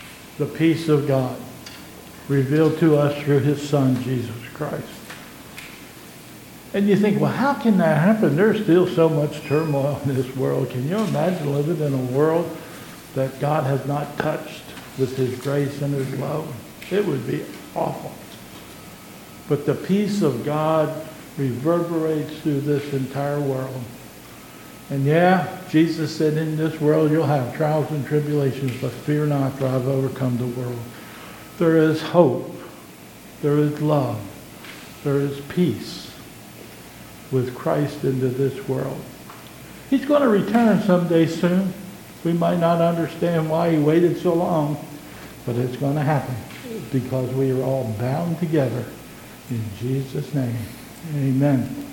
2022 Bethel Covid Time Service
Communion Service
Closing Hymn: "Shalom to You"